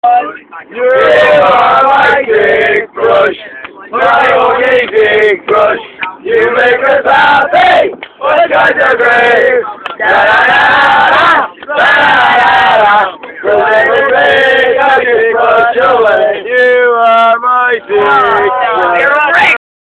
2 new chants. learn them well.